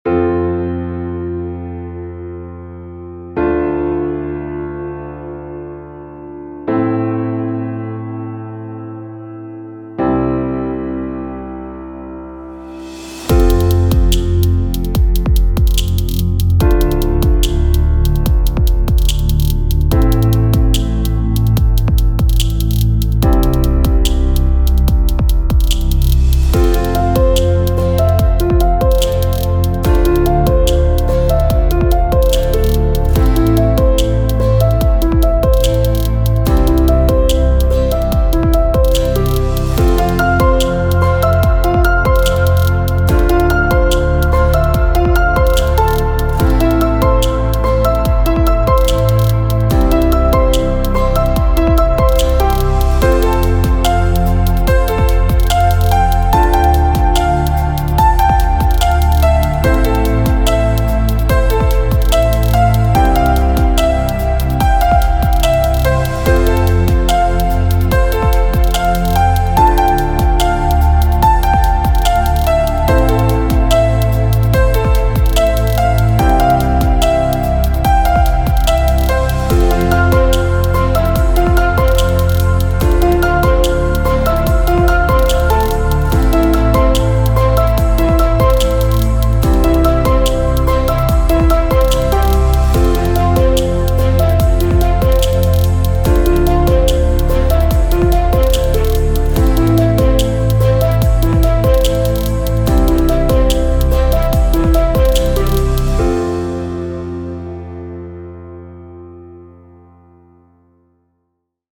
Ambient Piano.mp3